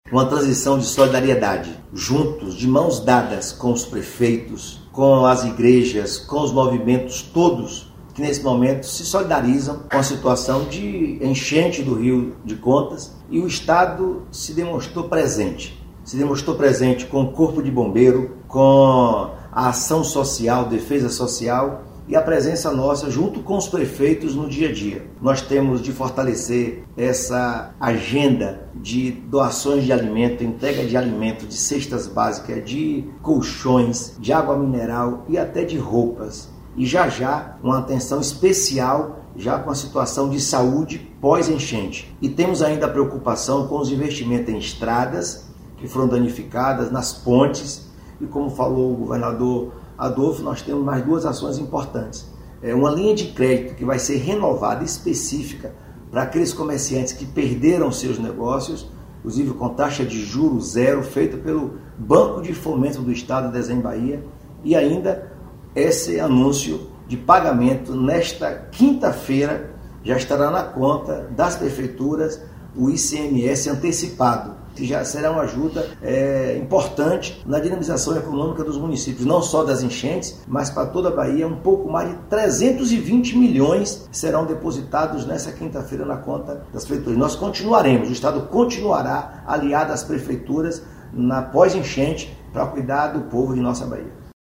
Sonora Governador da Bahia – enchentes em municípios